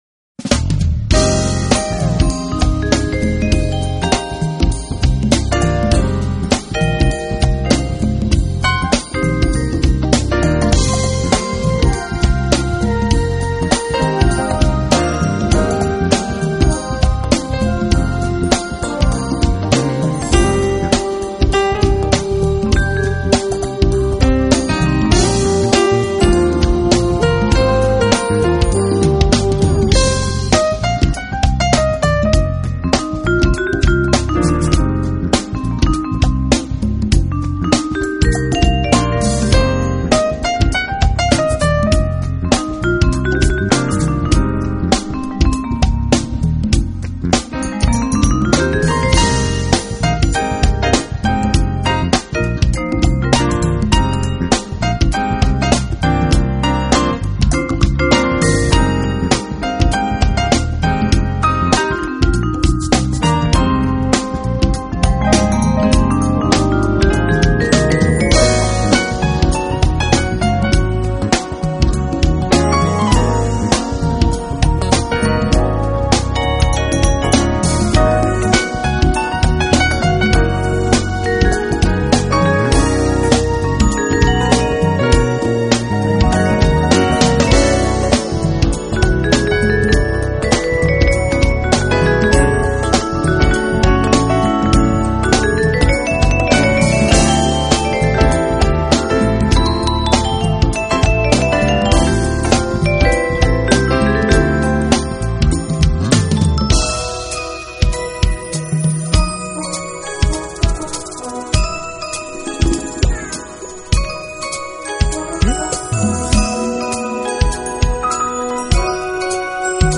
Genre : Jazz
Styles : Jazz Instrument,Piano